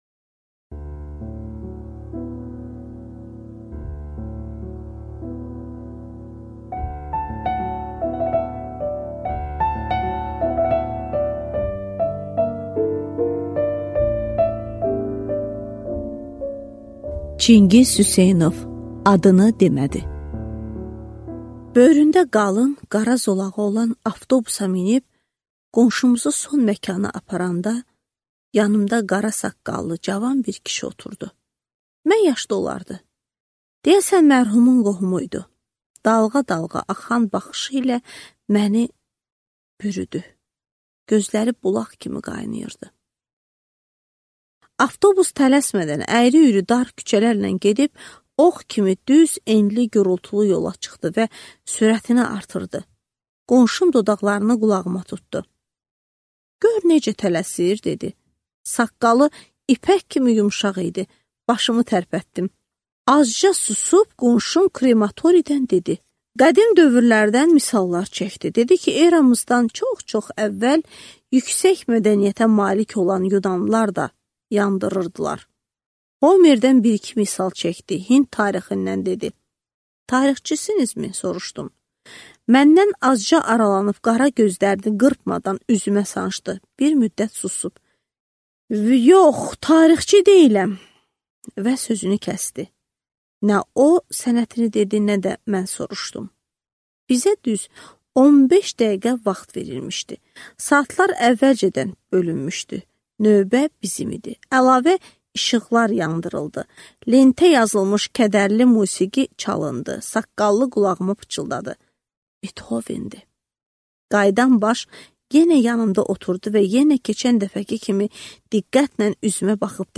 Аудиокнига Adını demədi | Библиотека аудиокниг